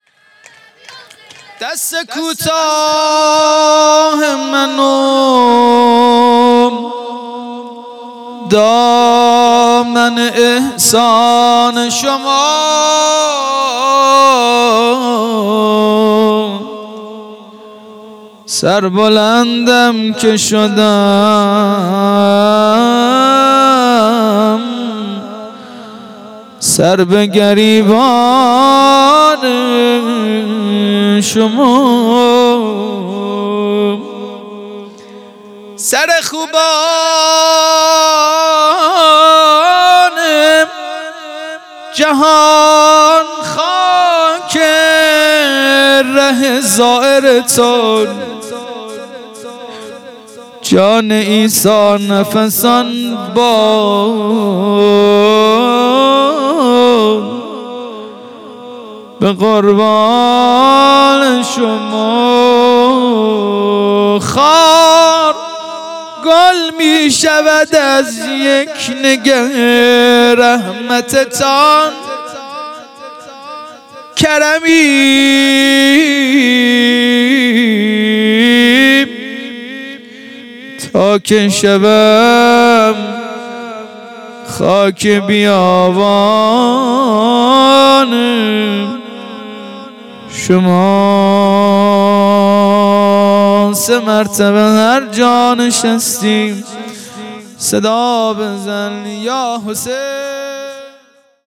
گروه هنری درخشان سازان الماس - روضه پایانی